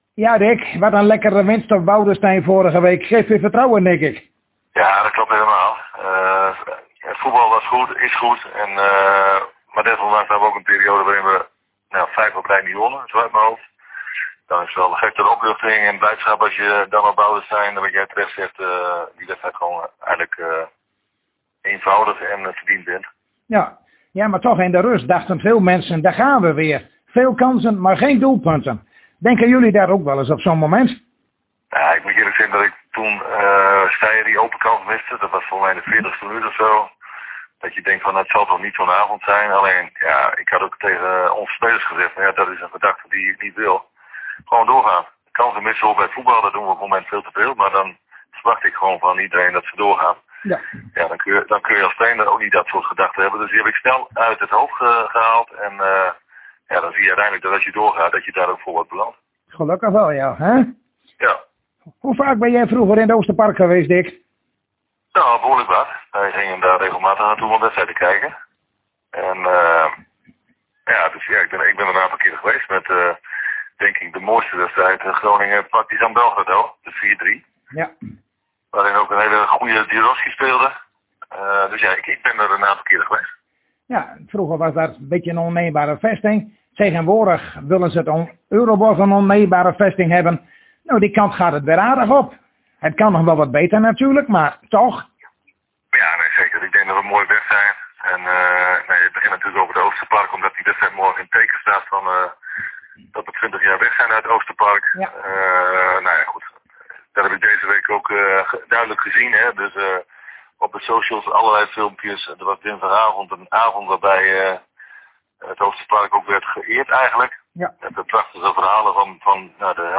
Zojuist spraken wij weer met Dick Lukkien en dat kunt u hier beluisteren.